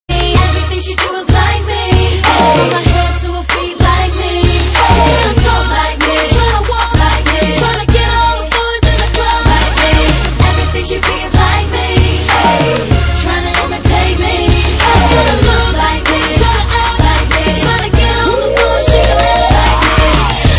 Rap & Hip Hop